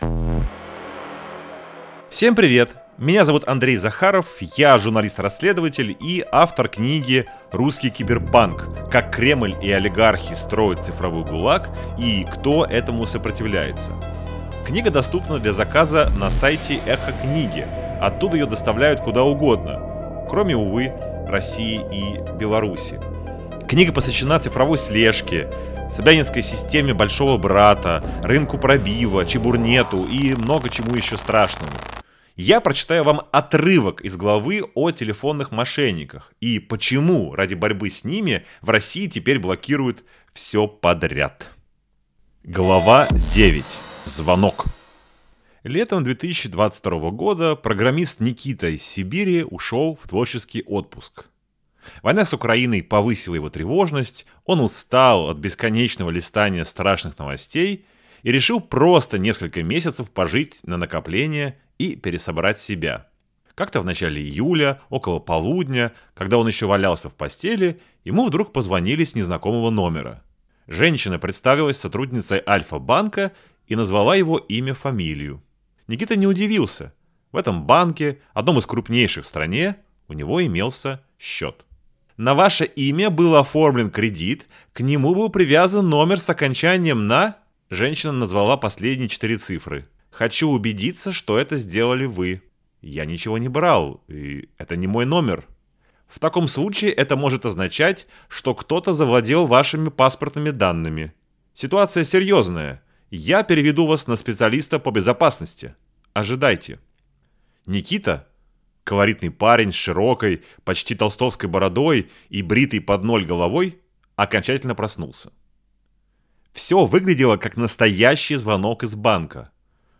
читает часть главы «Звонок» — о том, как телефонные мошенники обманом заставили программиста из Сибири снять со счета 3,5 миллиона рублей, которые он в итоге оказался должен банку. Во второй части главы — о том, с чего начиналось телефонное мошенничество в России, почему полиция не может его победить и как жертвой однажды стал скрипач Юрий Башмет.